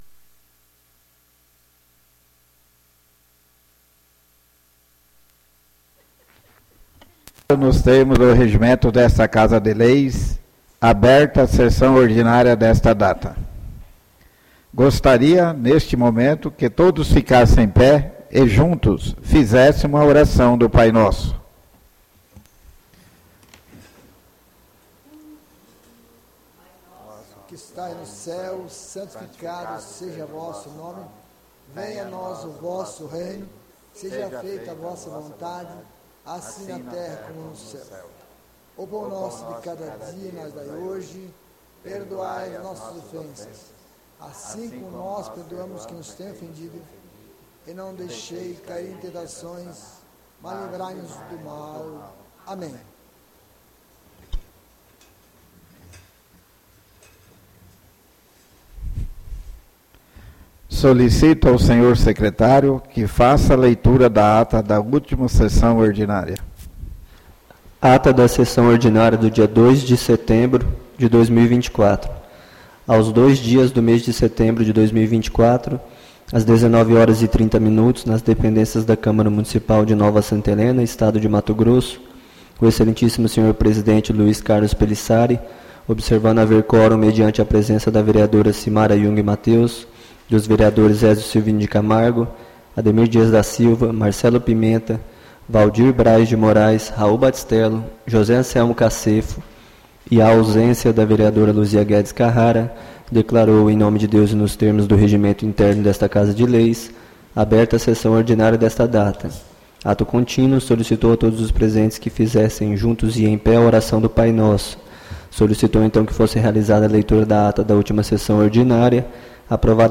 ÁUDIO SESSÃO 09-09-24 — CÂMARA MUNICIPAL DE NOVA SANTA HELENA - MT